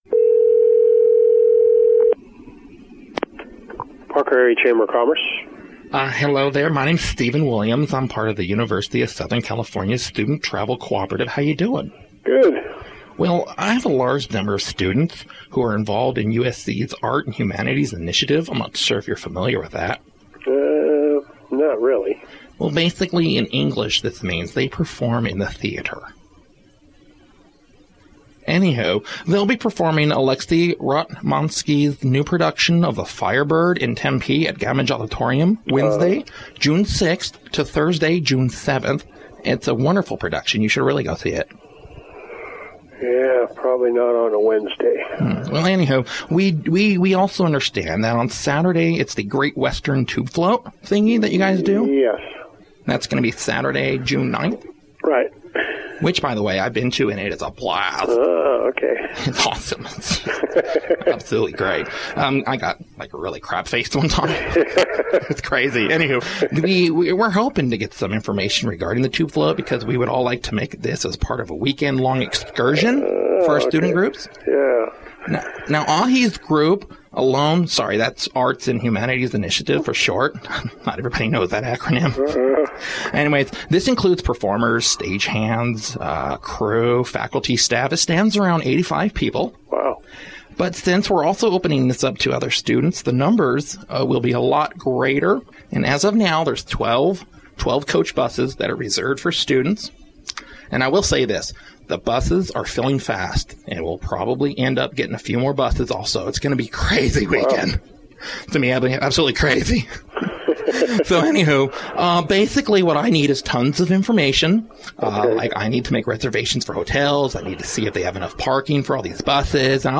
0:00 - A Tube Float prank call
31:18 - Tube Float commercial song 32:16 - Final tips